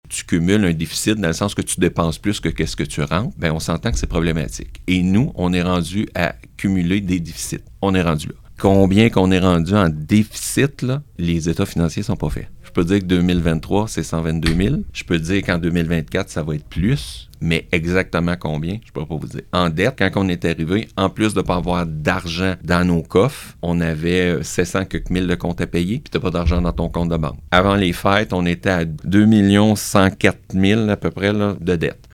Le maire de Montcerf-Lytton, Pierre Côté, s’est exprimé au micro de CHGA pour partager l’état des finances de sa municipalité avec les résidents. La dette de Montcerf-Lytton a dépassé 2 millions de dollars.